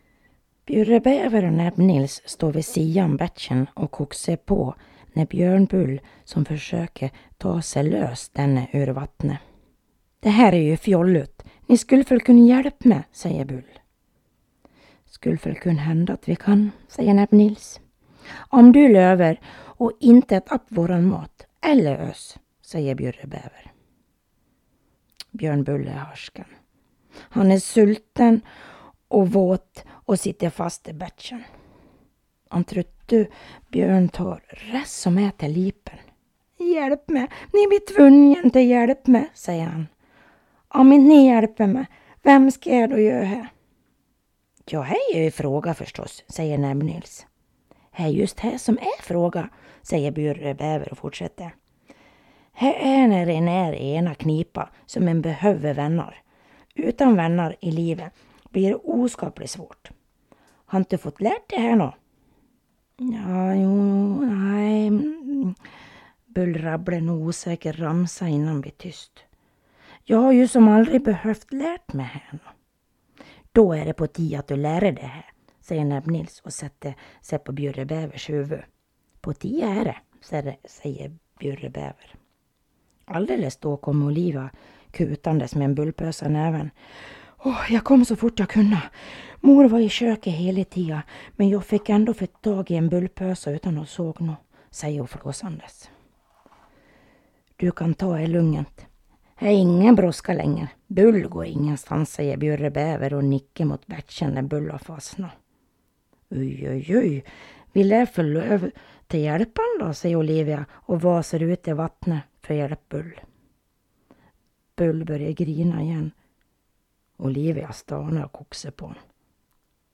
Utöver detta kan du lyssna på sagan – både på standardsvenska och bjursmål.
Station 8 – bjursmål
Station08_bjursmal.mp3